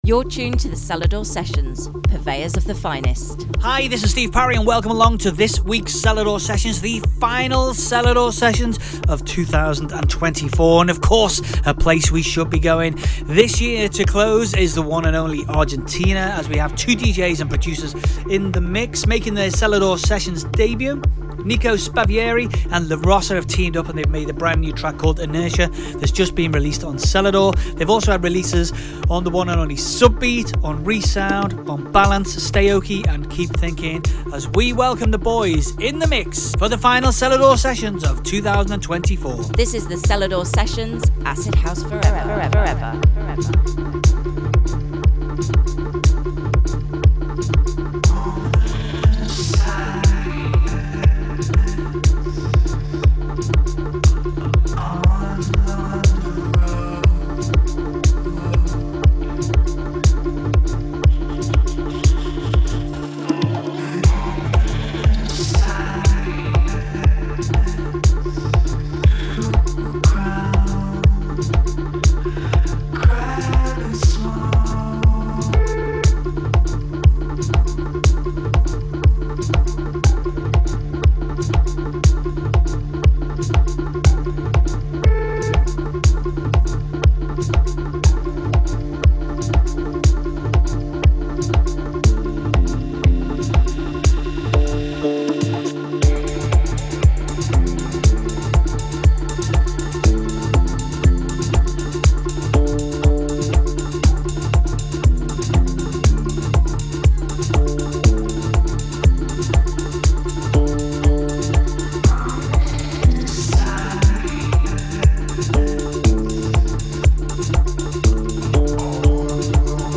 Also find other EDM Livesets, DJ Mixes and
Two Argentinean DJ’s and Producers
an hour of underground goodness